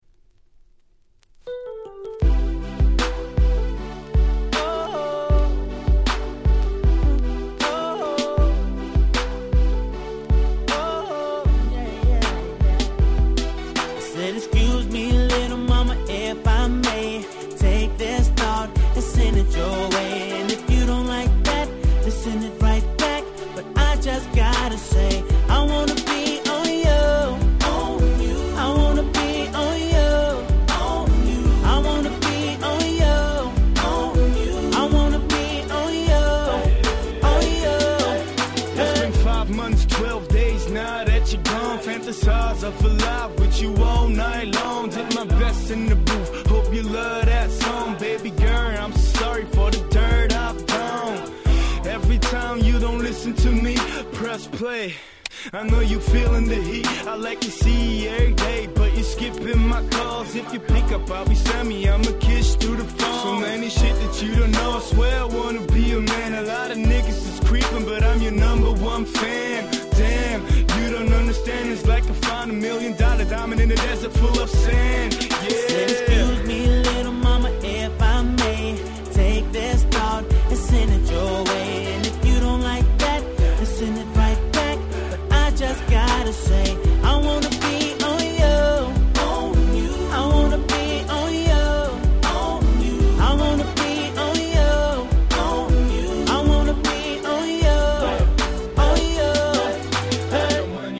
09' Nice R&B Compilation !!